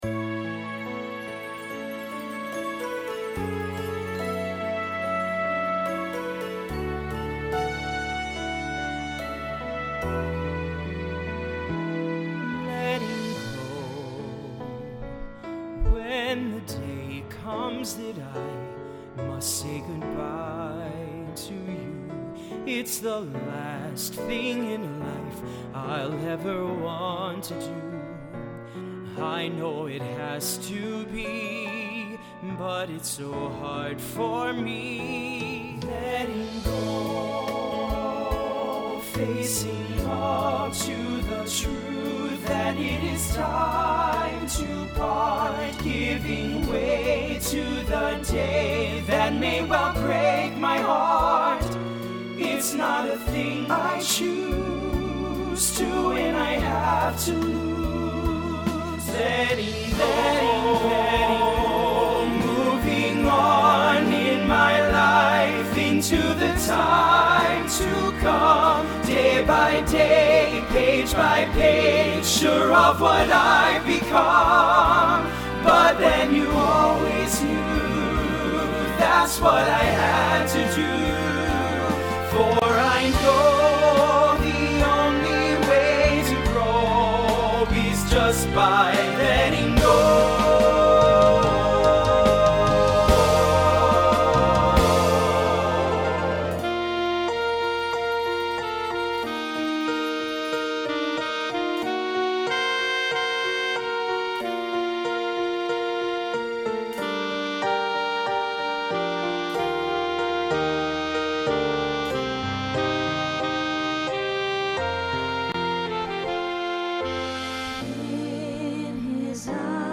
guys/girls feature ballad